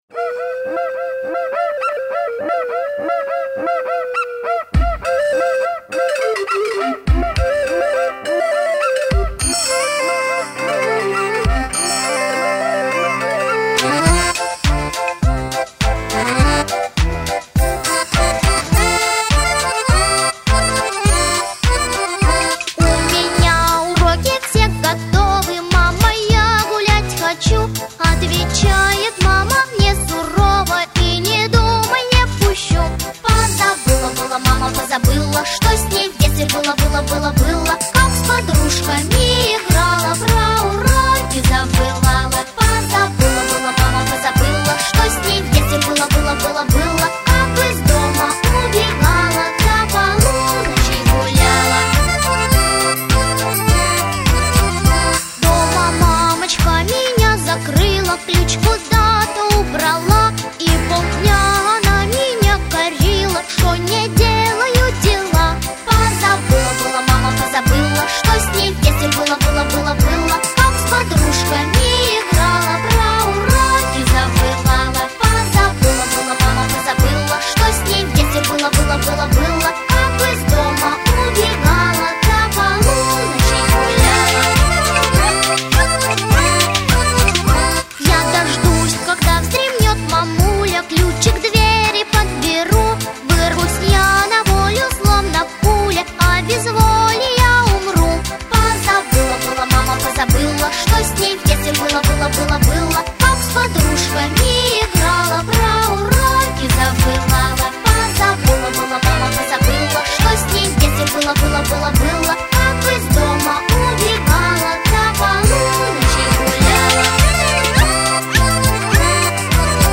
Это очень веселая песня, ее можно интересно обыграть.)